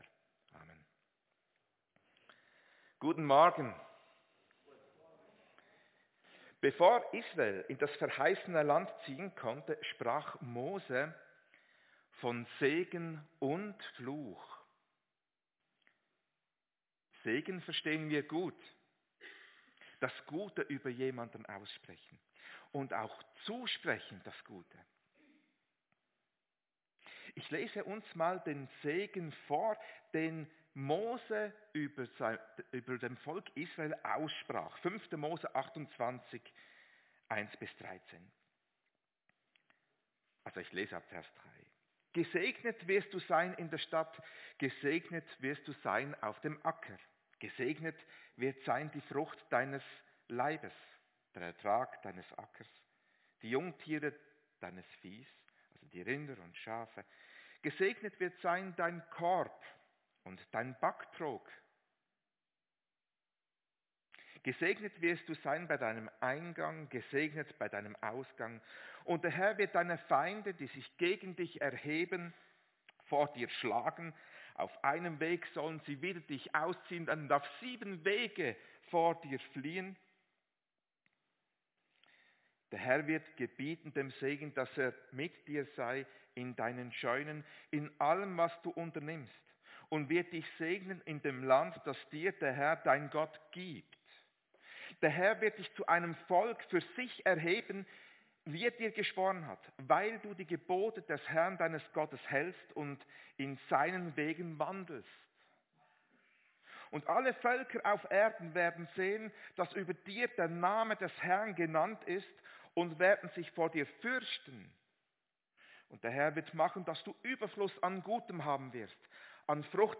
Predigt-5.5.24.mp3